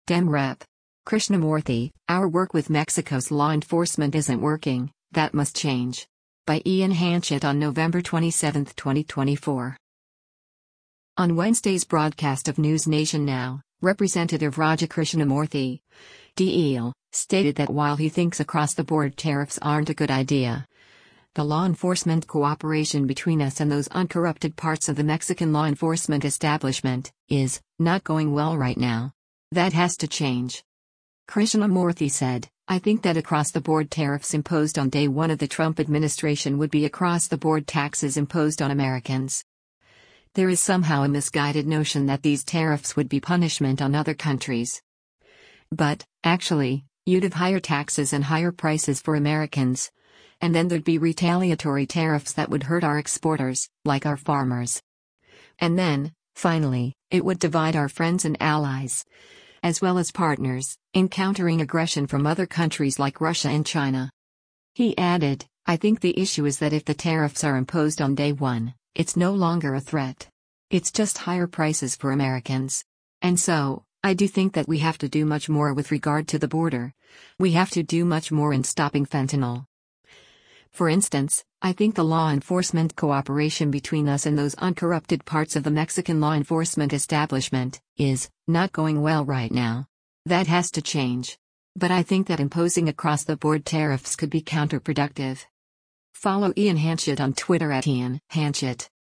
On Wednesday’s broadcast of “NewsNation Now,” Rep. Raja Krishnamoorthi (D-IL) stated that while he thinks across-the-board tariffs aren’t a good idea, “the law enforcement cooperation between us and those uncorrupted parts of the Mexican law enforcement establishment [is] not going well right now. That has to change.”